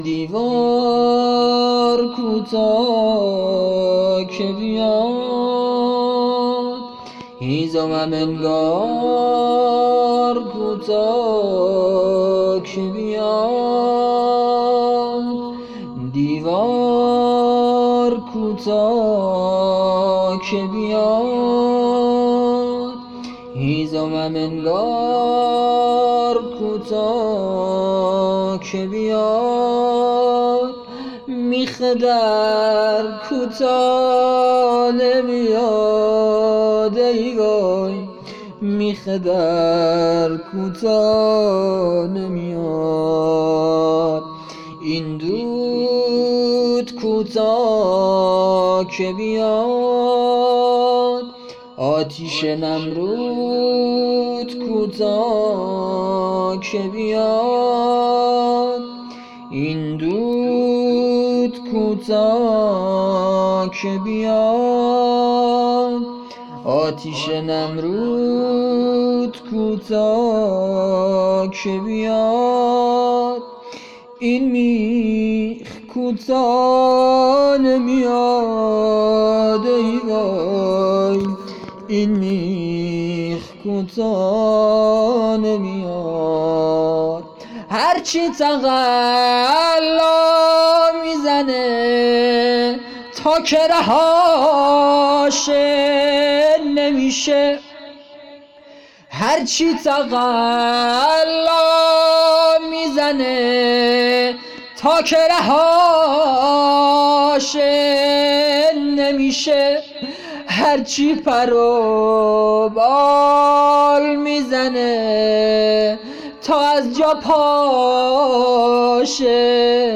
سبک روضه